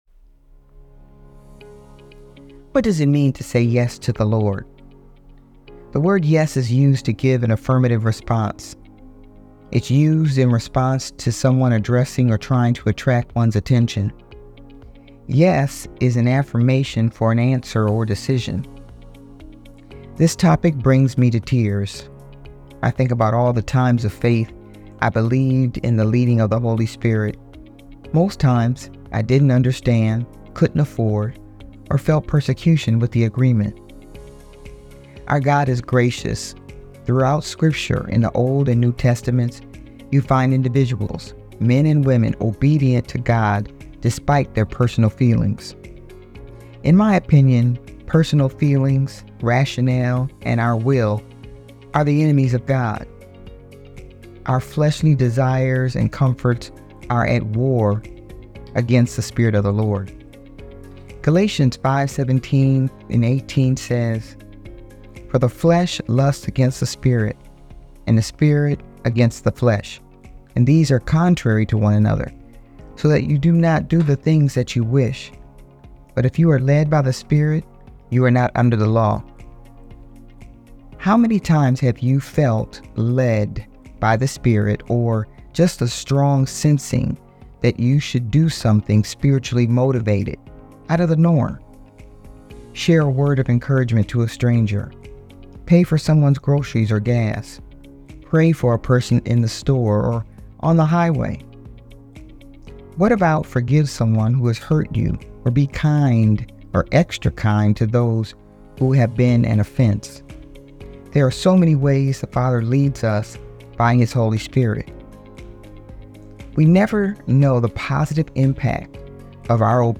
She shares words of inspiration, encouragement, and prayer.